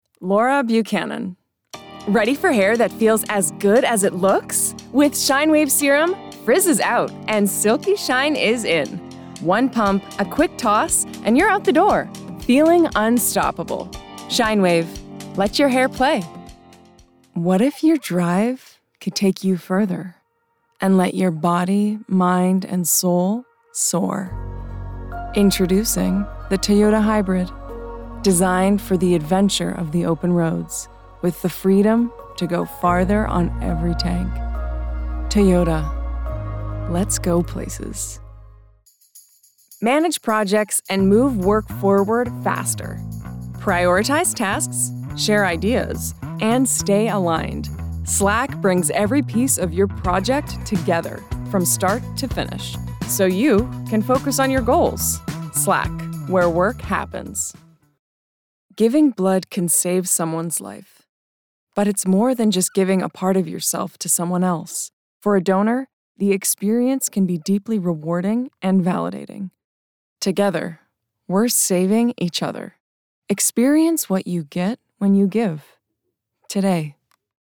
Publicités - ANG